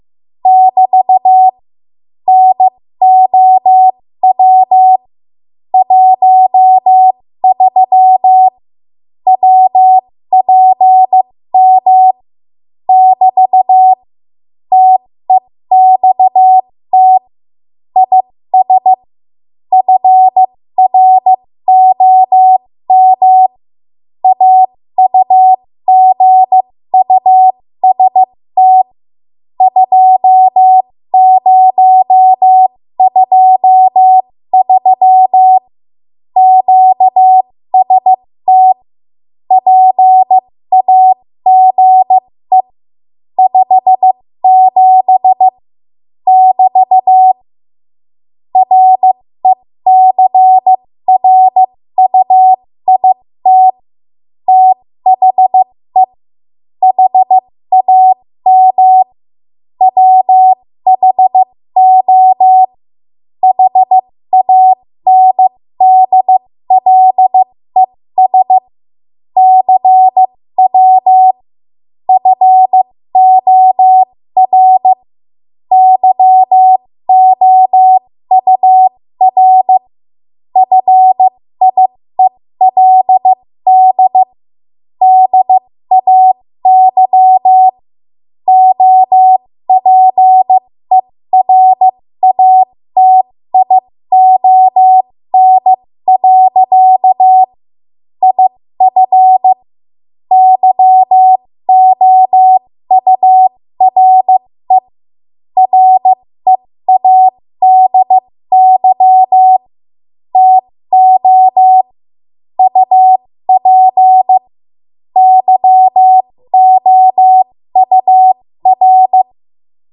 13 WPM Code Archive